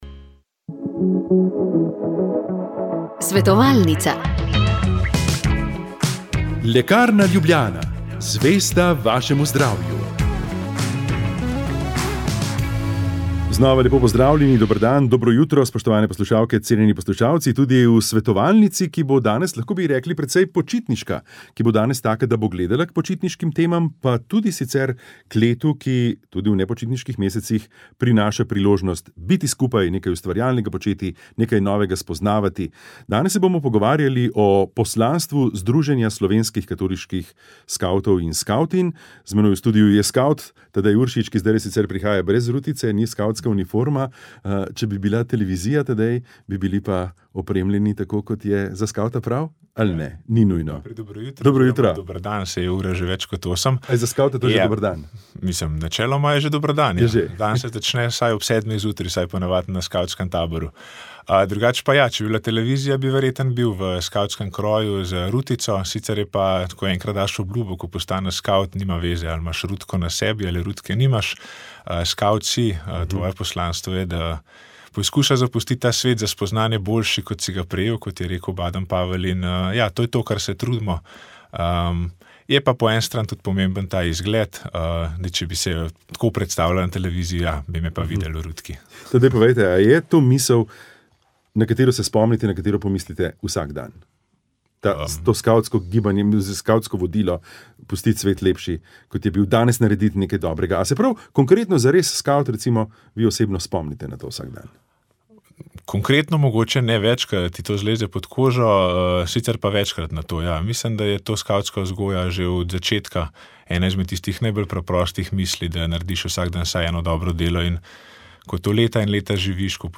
Na šesto velikonočno nedeljo smo prenašali sveto mašo iz celjske stolnice svetega Danijela.
Pri maši so sodelovali tudi skavti in skavtinje stega 2 iz Celja.